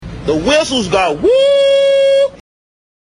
PLAY Whistles go woo
whistles-go-woo.mp3